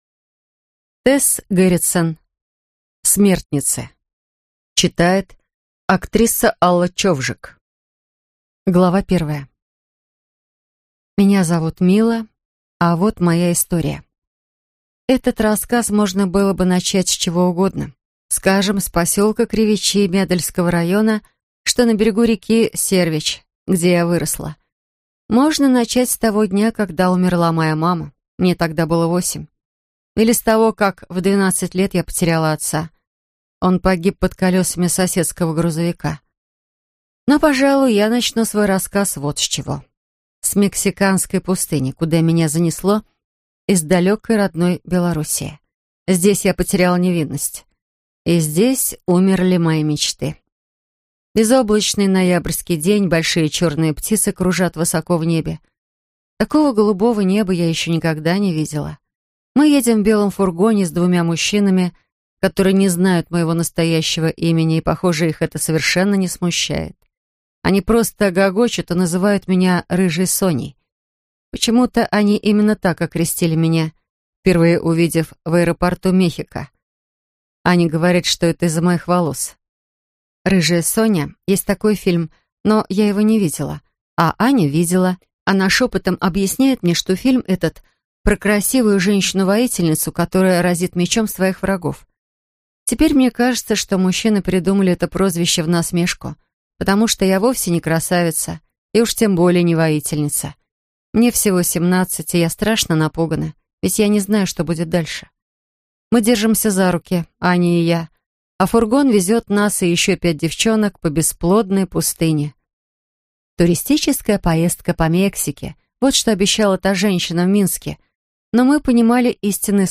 Аудиокнига Смертницы - купить, скачать и слушать онлайн | КнигоПоиск